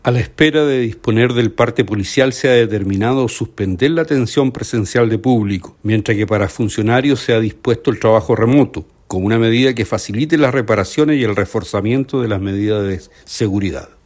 El Seremi de las Culturas, Artes y Patrimonio, Óscar Mendoza, indicó que los funcionarios fueron enviados a sus domicilios para hacer teletrabajo, mientras se refuerzan las medidas de seguridad.